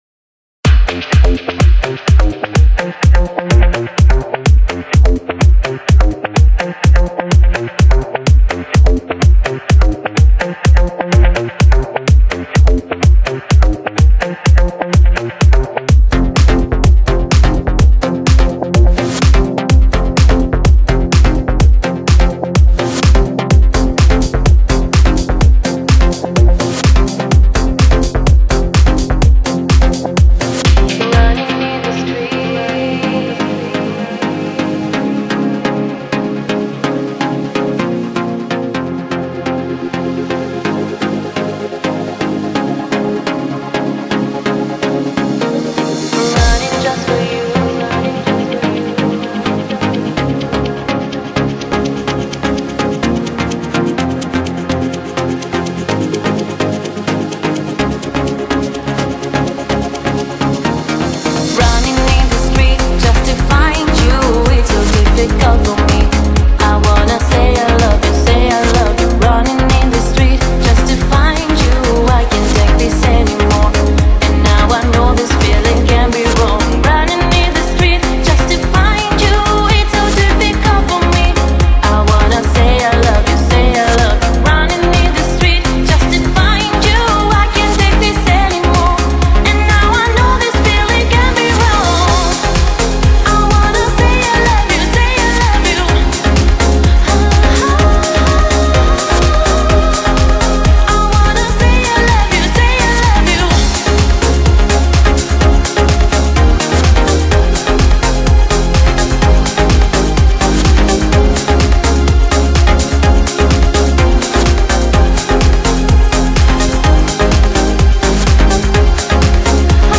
Genre: Dance, Pop | 20 Tracks | VBR 320 kbps | 209.5 MB | MU